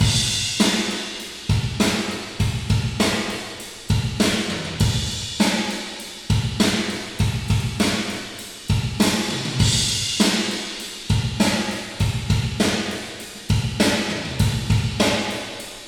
И как это я не догадался, не вспомнил, ведь это классика жанра - барабаны в plate.